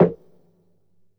TM-88 Snare #14.wav